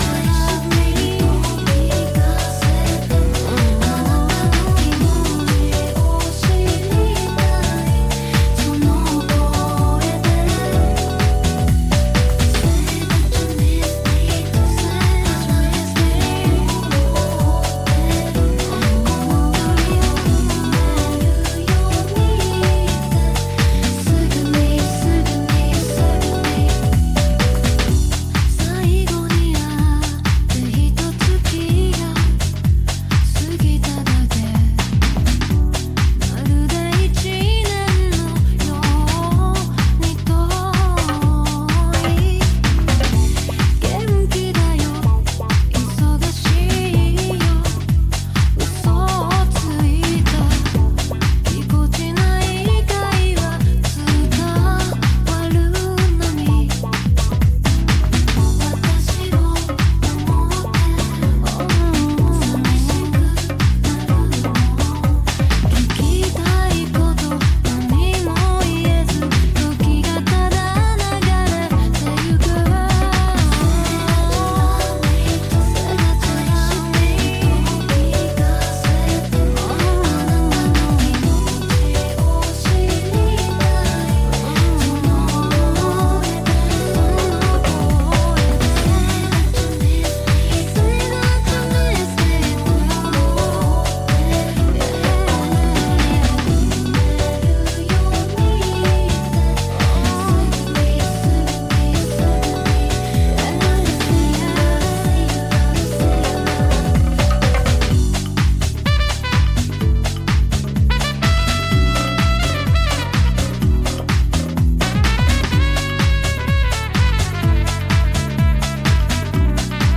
ジャンル(スタイル) DEEP HOUSE